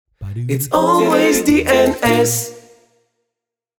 And now it’s a jingle, for all to enjoy.
A little melody to complement your frustration.
in Ukraine